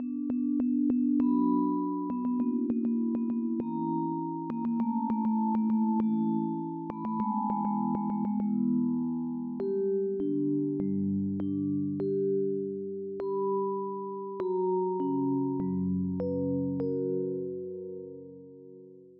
Key written in: B Minor
How many parts: 5
Type: Female Barbershop (incl. SAI, HI, etc)